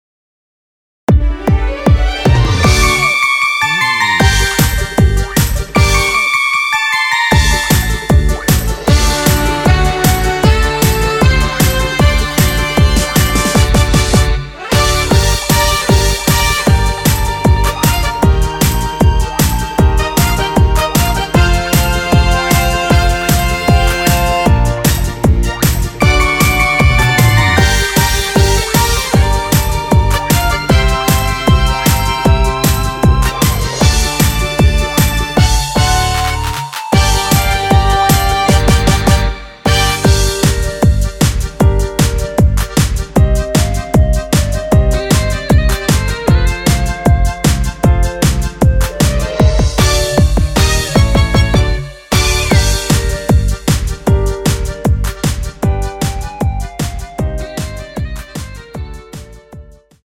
원키에서(+5)올린 멜로디 포함된 MR입니다.
Gm
앞부분30초, 뒷부분30초씩 편집해서 올려 드리고 있습니다.
중간에 음이 끈어지고 다시 나오는 이유는